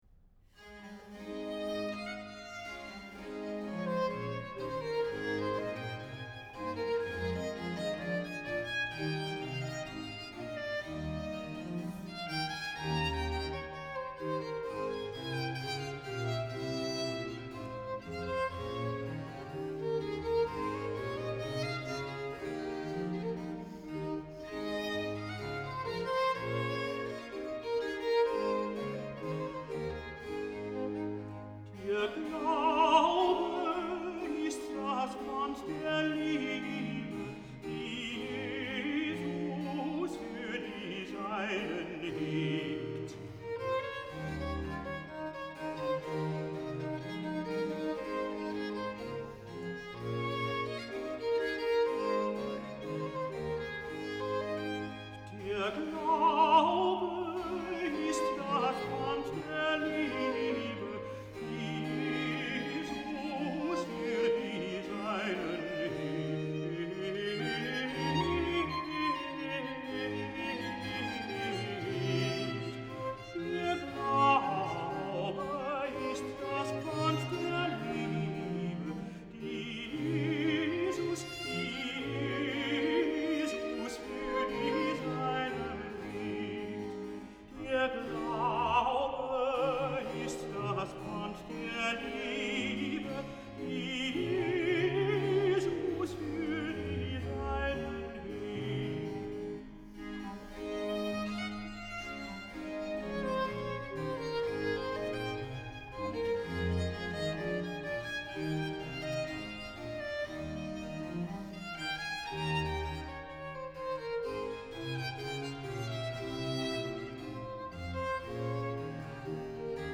Entzückt lauscht der Hörer in der Arie für Tenor der wunderbaren Textaussage in Satz 2: „Der Glaube ist das Pfand der Liebe“ mit dem Violin-Solo.
Satz 2 für Tenor
bach_-bwv-37-_wer-da-glaubet-und-getauft-wird_-2-satz-tenor-der-glaube-ist-das-pfand-der-liebe.mp3